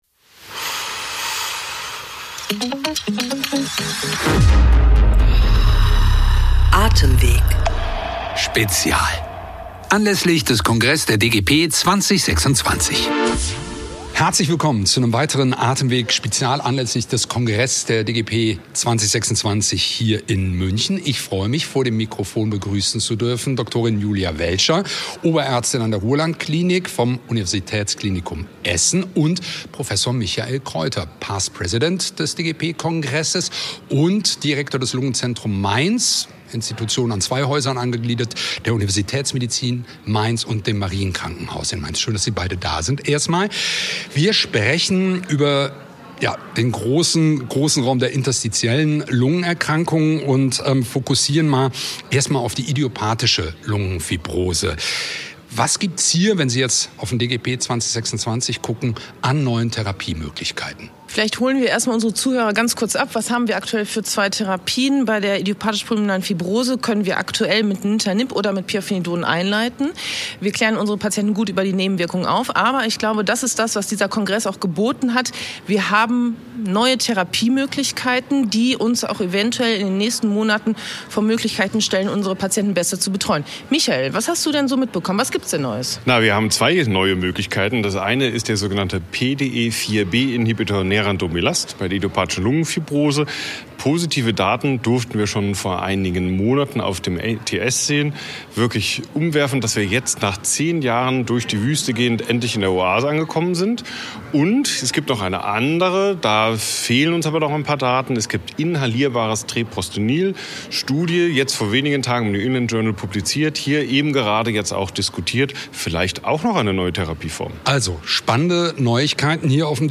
Wir berichten live vom 66. DGP-Kongress in München: In unserer